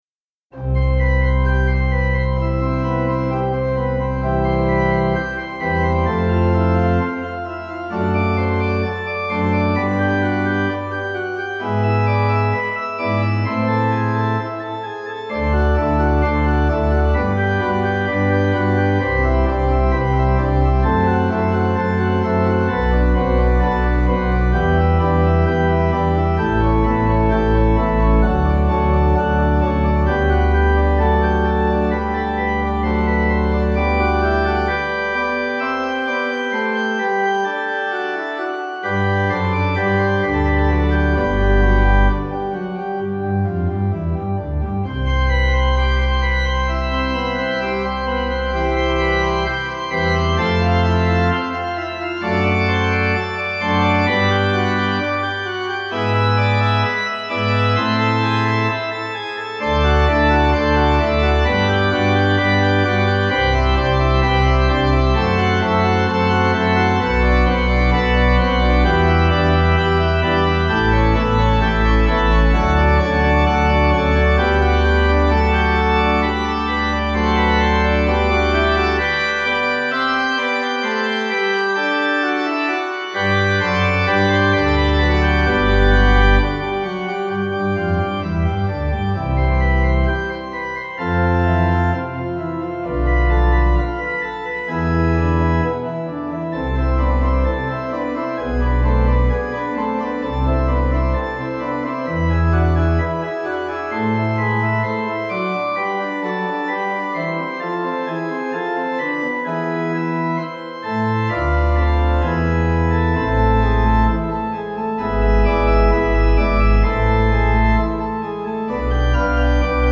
Organ
Easy Listening   C